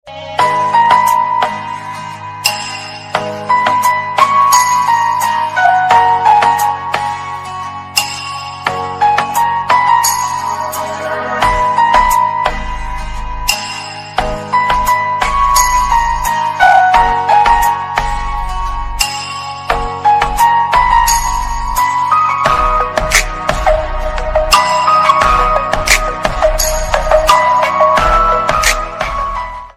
Category: Music Ringtones